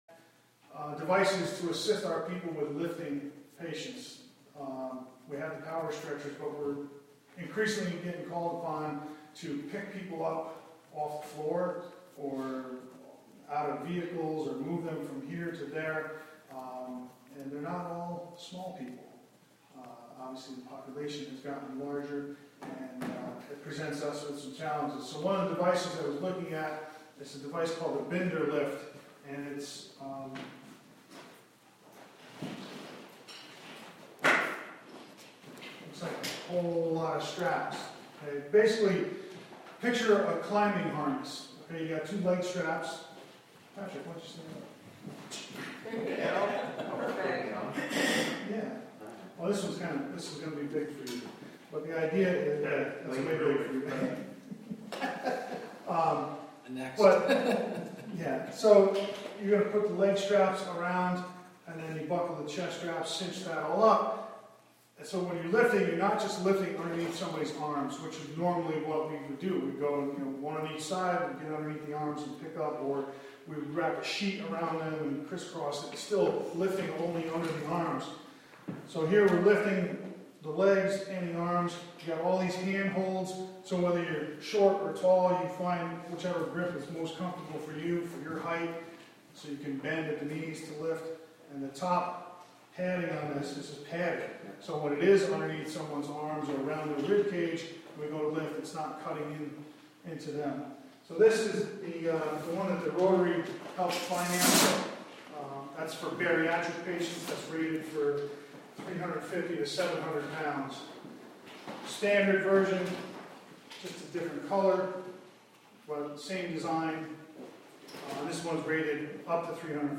Wave Farm | Live from the Town of Catskill: May Town Board Meeting
Live from the Town of Catskill: May Town Board Meeting (Audio)